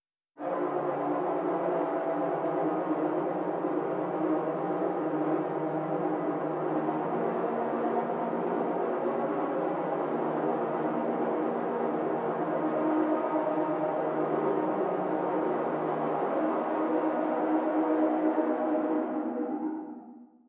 blackhole1.wav